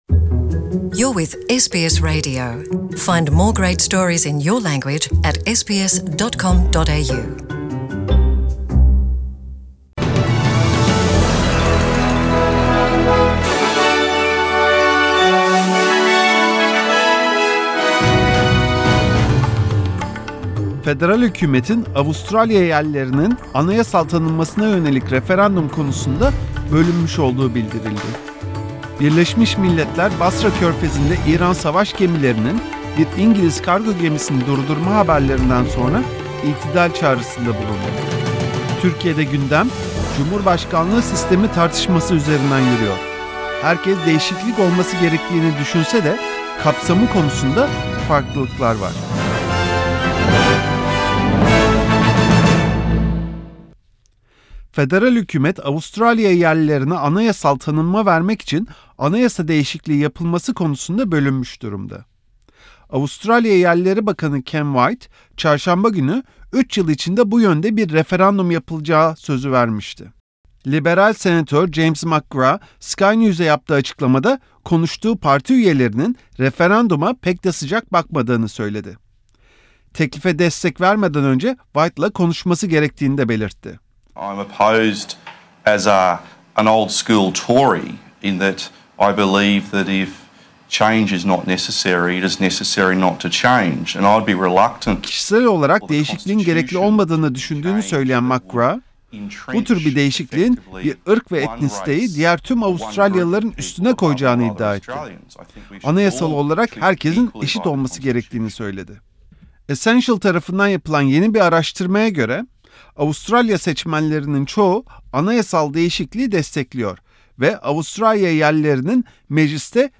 SBS Radyosu Türkçe Programı'nda Avustralya, Türkiye ve dünyadan haberler. Başlıklar: ** Federal hükümetin, Avustralya yerlilerinin anayasal tanınmasına yönelik referandum konusunda bölündüğü bildirildi. ** Birleşmiş Milletler, Basra körfezinde, İran savaş gemilerinin bir İngiliz kargo gemisini durdurmaya çalışmasından sonra itidal çağrısında bulundu. ** Türkiye’de gündem, cumhurbaşkanlığı sistemi tartyışması üzerinden yürüyor.